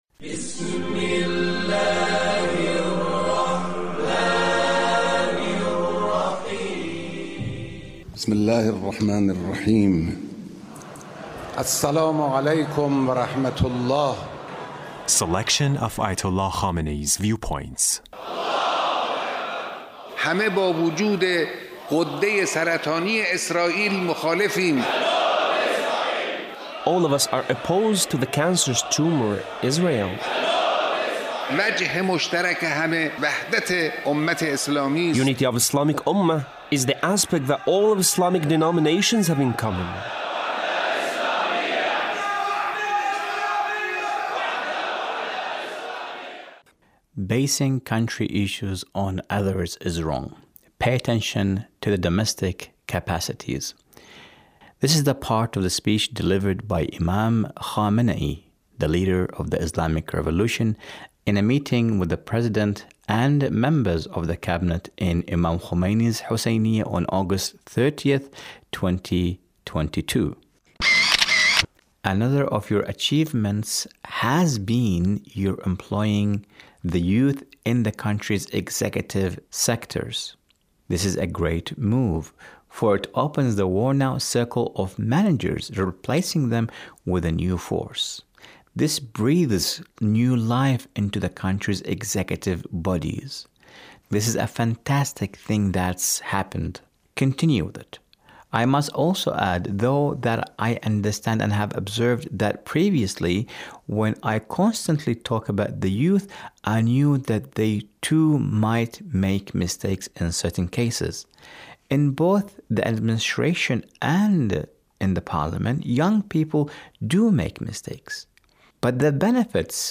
Leader's Speech on a Gathering with Friday Prayer Leaders